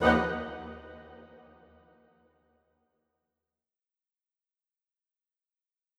Woodwind Ensemble Hit 9
Bring new life to your videos with professional orchestral sounds.
A staccato is a short and fast sound that any orchestral instrument can make.  In this sample, you hear four sections of four different instruments from the orchestra which are violins, violas, violoncellos and double basses.
Woodwind-Ensemble-Hit-9.wav